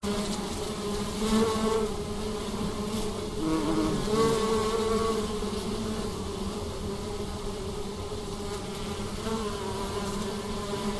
Stemningslyd til billedet nedenstående?
Lyden_af_bier.mp3